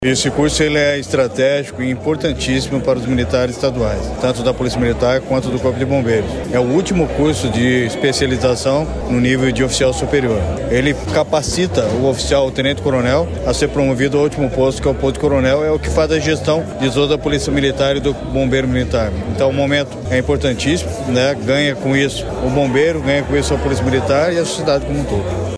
Sonora do secretário da Segurança Pública, Hudson Teixeira, sobre a formatura de novos oficiais da PM e do Corpo de Bombeiros